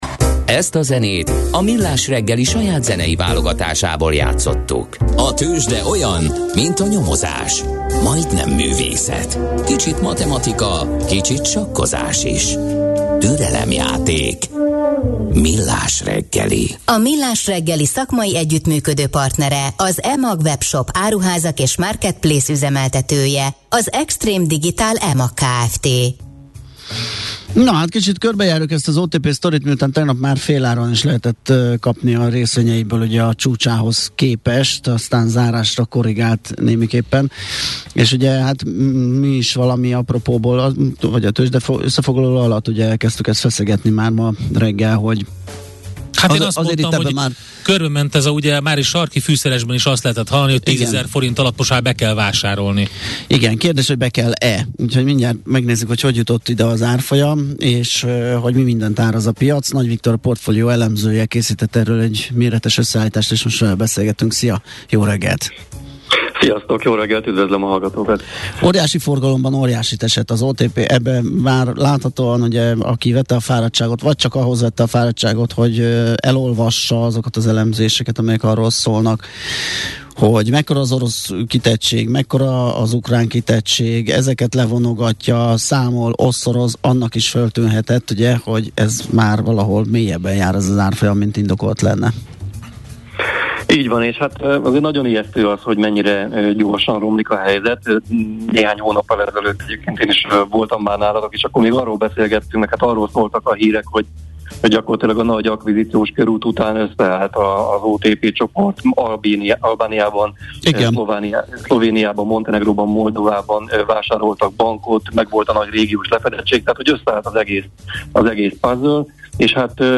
Műfaj: Blues.